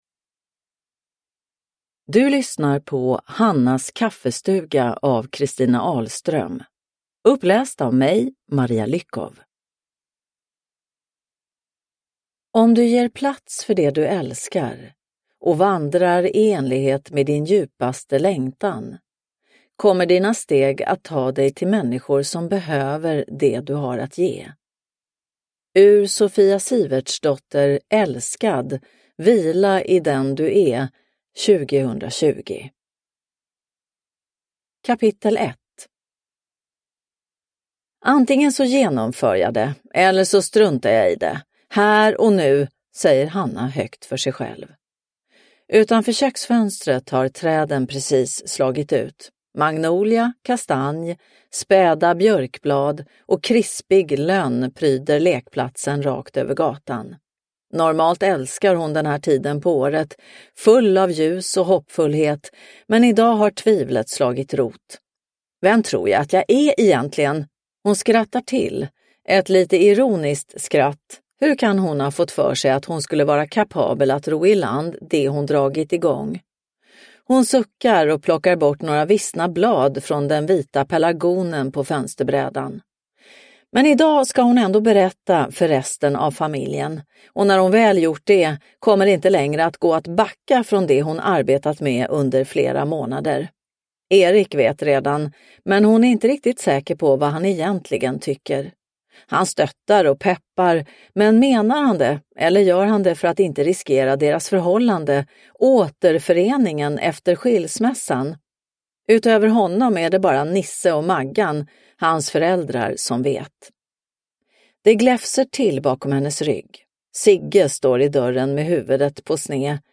Hannas kaffestuga – Ljudbok – Laddas ner